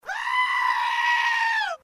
Screaming Goat Sound Effect Free Download
Screaming Goat